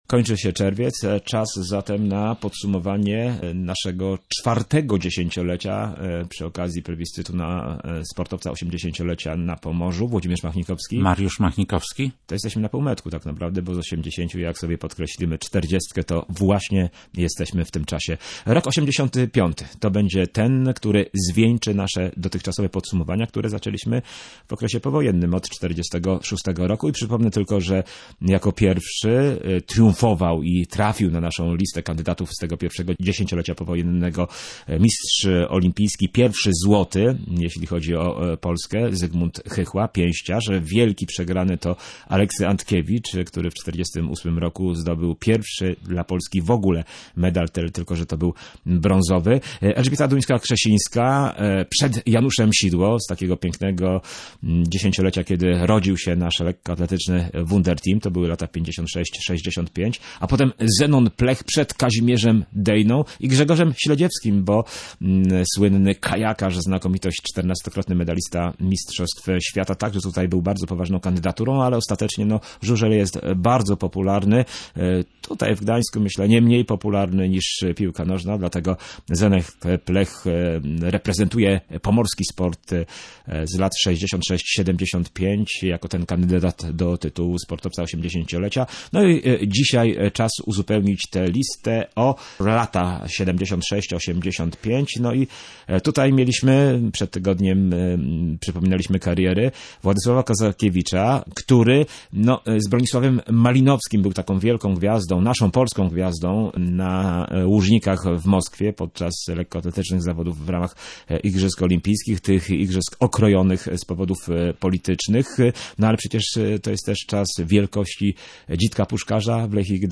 dyskusji